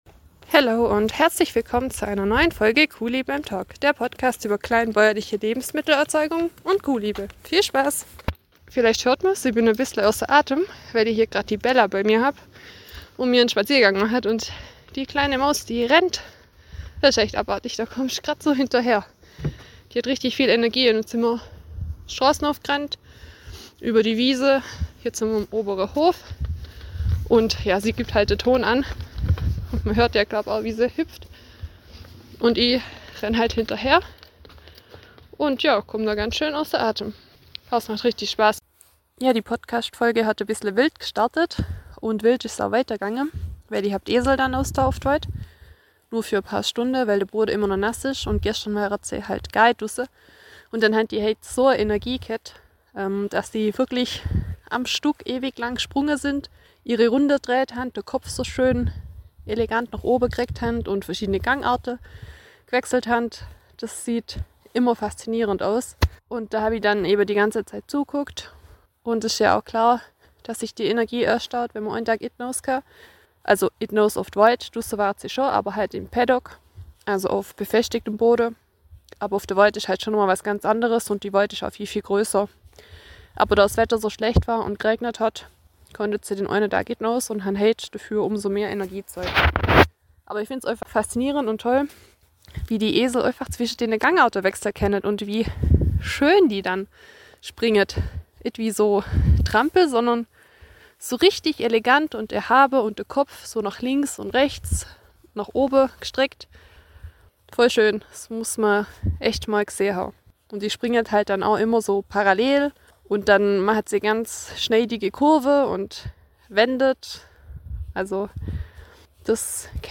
Bitte entschuldigt die teilweise schlechte Qualität des Tons... scheinbar eignet sich das neue Handy nicht wirklich für Podcastaufnahmen.